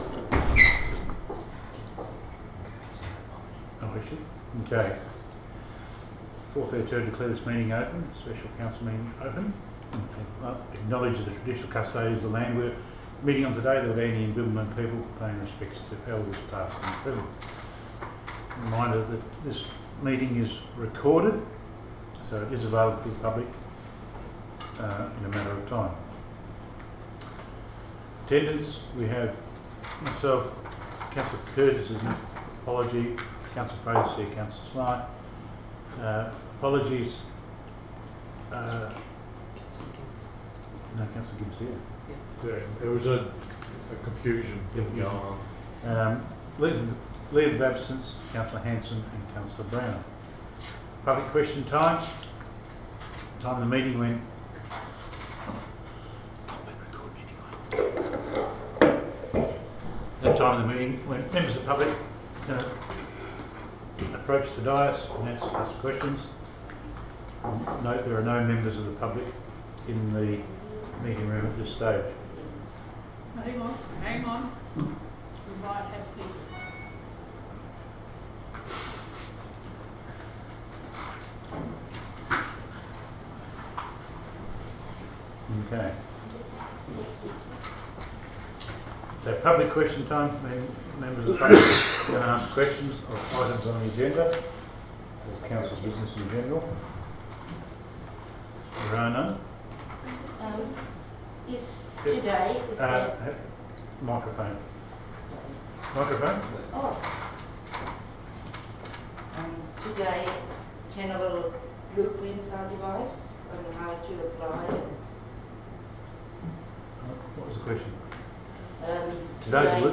Location: Council Chambers, 15 Adam Street, Nannup